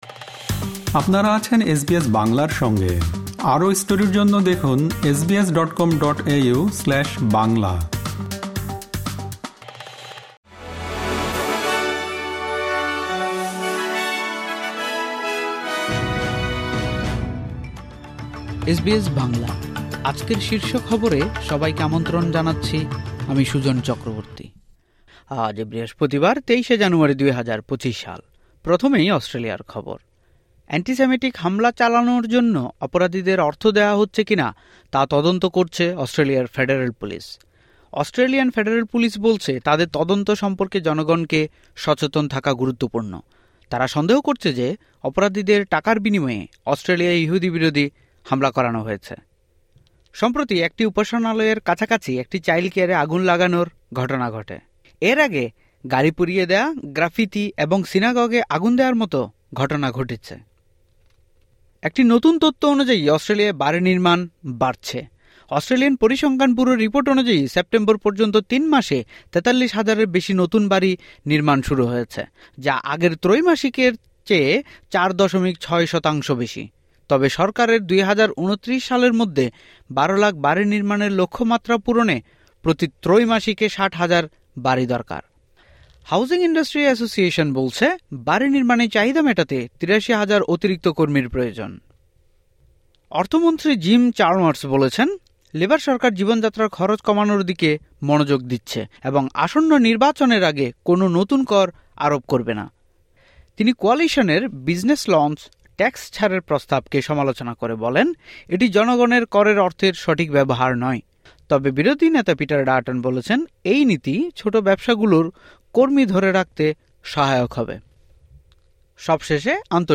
এসবিএস বাংলা শীর্ষ খবর: ২৩ জানুয়ারি, ২০২৫